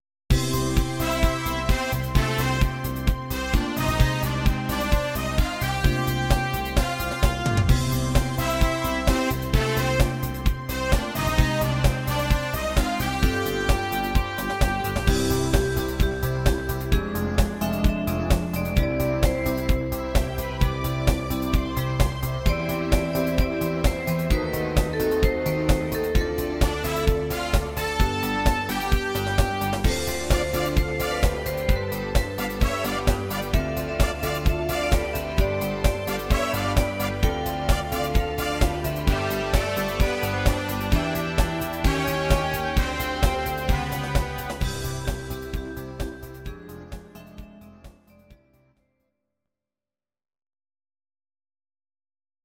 These are MP3 versions of our MIDI file catalogue.
dance mix